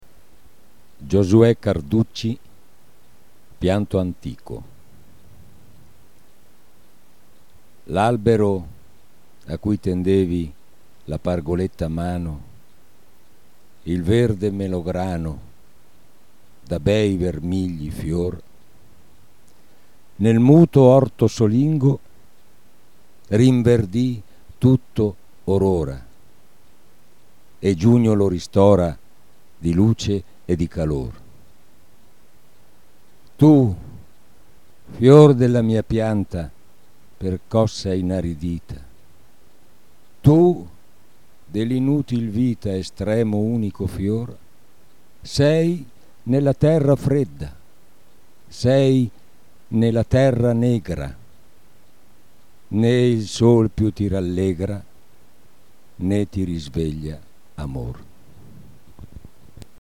Suoni poetici » Poesie recitate da artisti